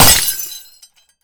glass_smashable_small_break_03.wav